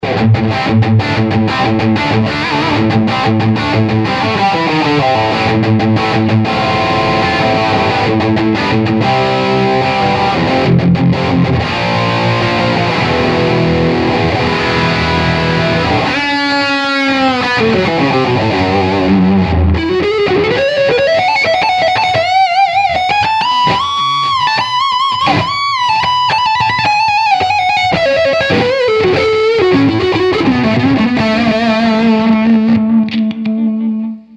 O Falante Crazy Diamond da BGT SPEAKER possui médios controlados e suaves, graves bem presentes e firmes, médios agudos cristalino e não ardido.
DRIVE